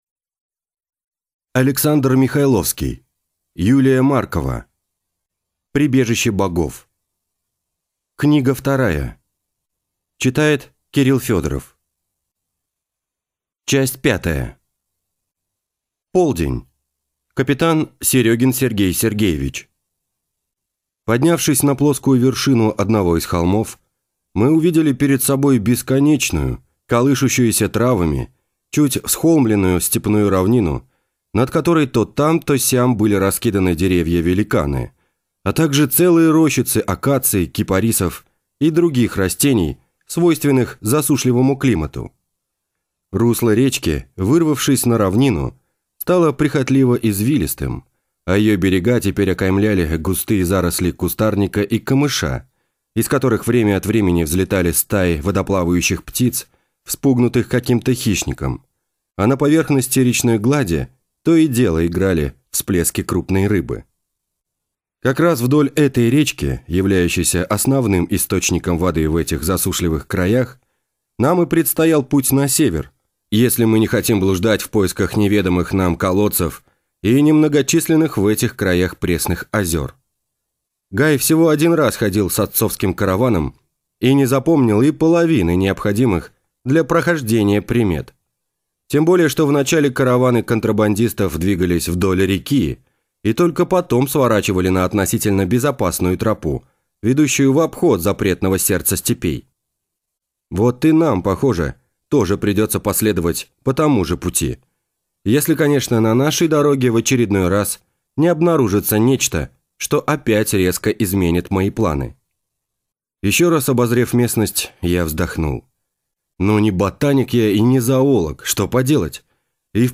Аудиокнига Прибежище богов | Библиотека аудиокниг